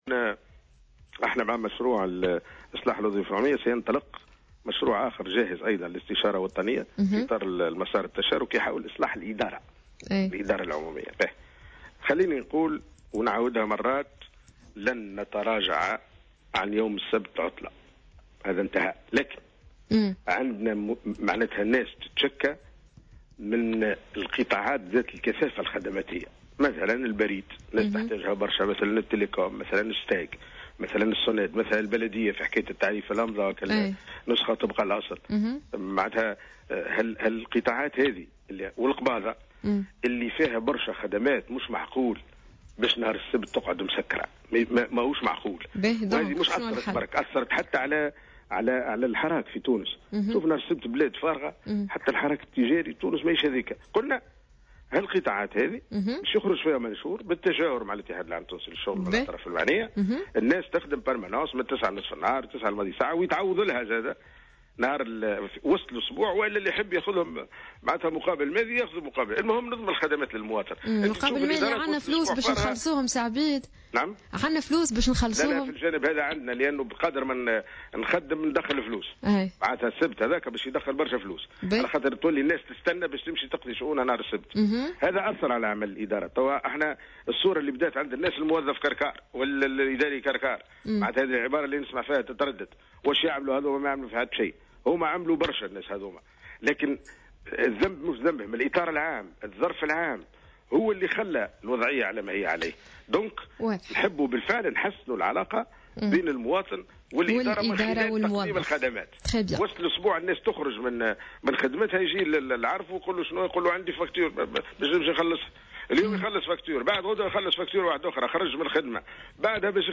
وأضاف الوزير في تصريح اليوم ل"الجوهرة أف أم" أنه لن يتم التراجع عن يوم السبت كيوم عطلة، لكن مع ضرورة استمرار تأمين الخدمات للمواطن، مشيرا إلى أنه سيتم إصدار منشور بالتشاور مع الاتحاد العام التونسي للشغل لتأمين حصص استمرار في هذه المؤسسات يوم السبت.